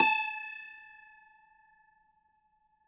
53r-pno17-A3.aif